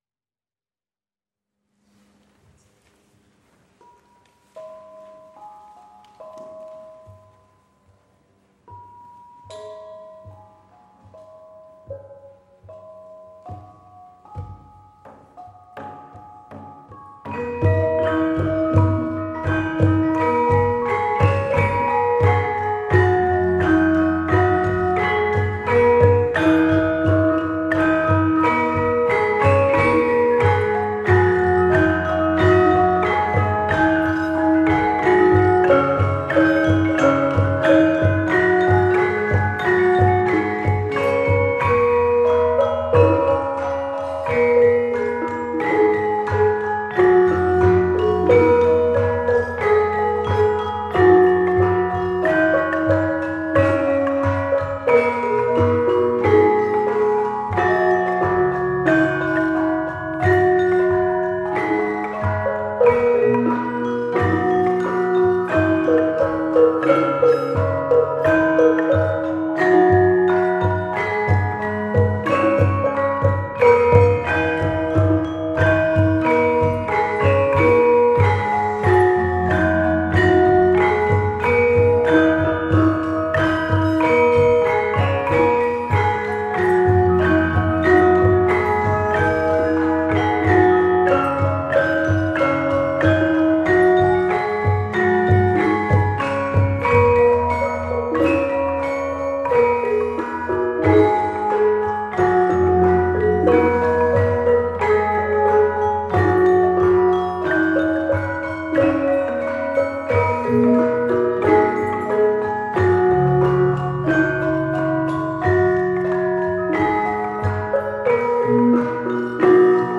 Group:  Gamelan Nyai Saraswati
Hill Hall
violin
cello
This concert was the last concert in a festival celebrating the legacy of Black Mountain College.
for Violin and Cello and Javanese Gamelan